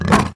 spawners_mobs_uruk_hai_attack.2.ogg